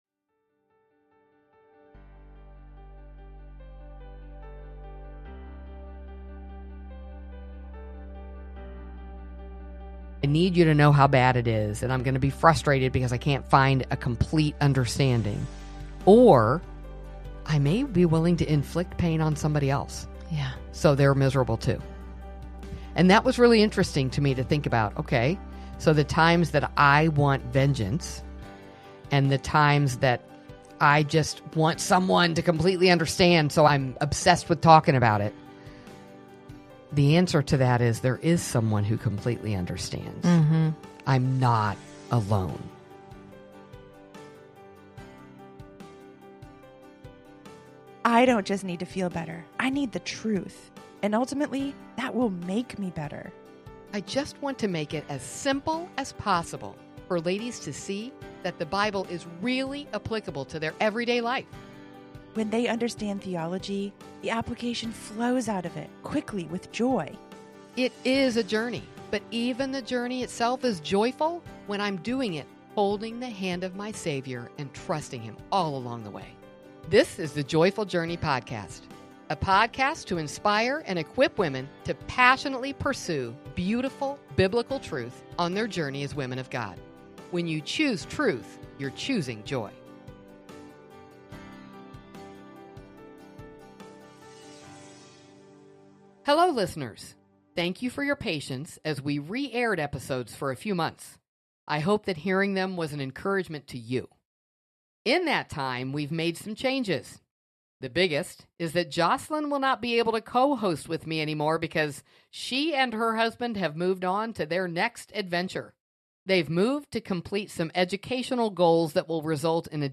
This discussion reveals the hope we have in Christ’s work for us, and how we can extend that same hope to others who have wronged us.